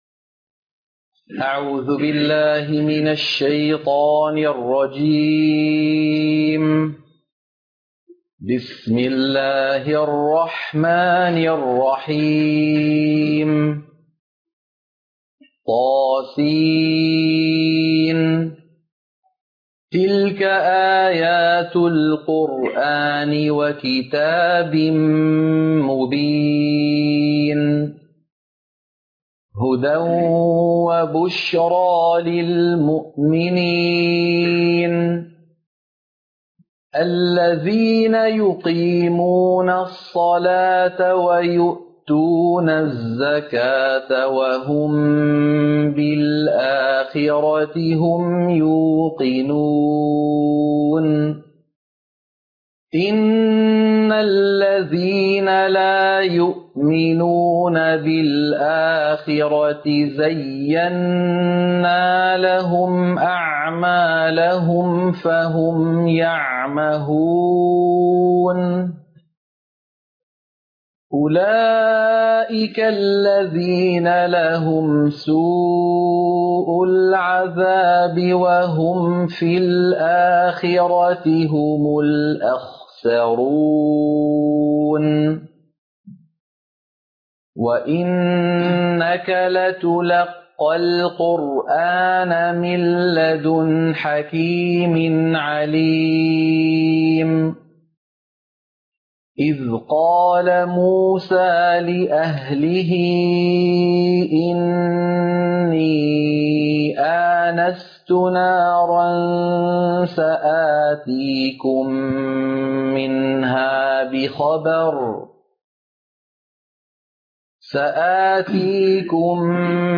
سورة النمل - القراءة المنهجية